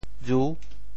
裕 部首拼音 部首 衤 总笔划 12 部外笔划 7 普通话 yù 潮州发音 潮州 ru6 文 中文解释 裕 <形> (形声,从衣,谷声。